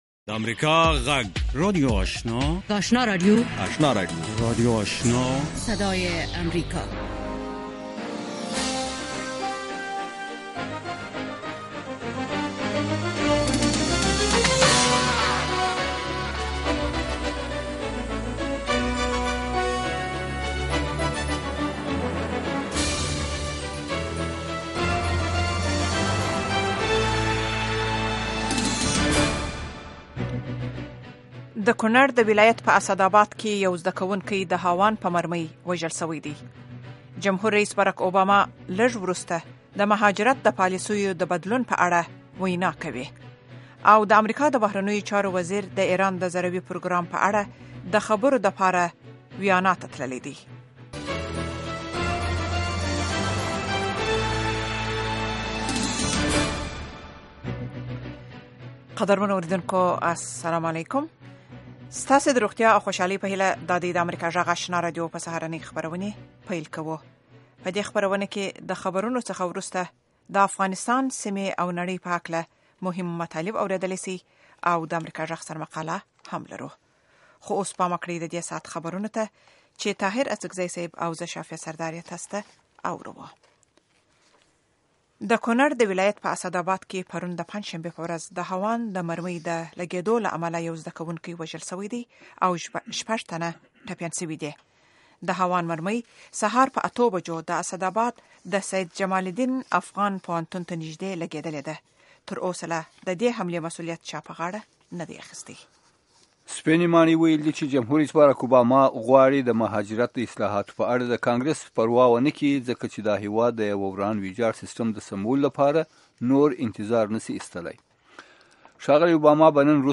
لومړنۍ سهارنۍ خبري خپرونه